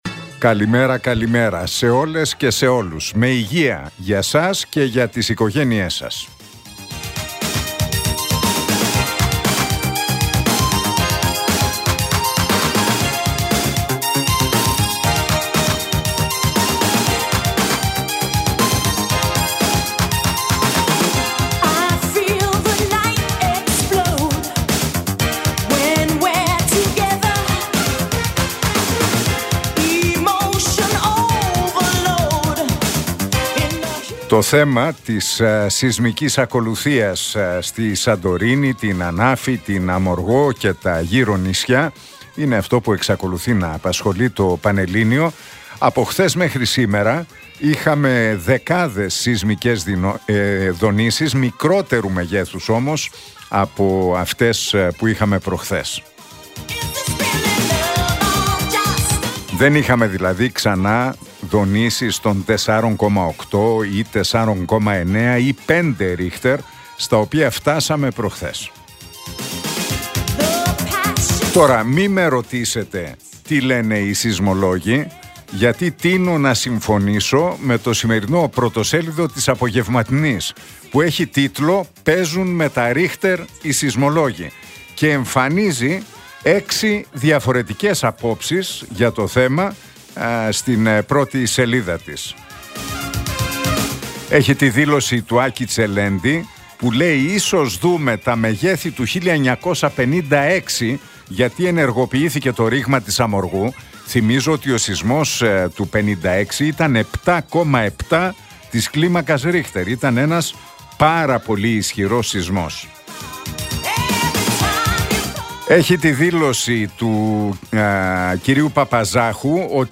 Ακούστε το σχόλιο του Νίκου Χατζηνικολάου στον ραδιοφωνικό σταθμό RealFm 97,8, την Τετάρτη 5 Φεβρουαρίου 2025.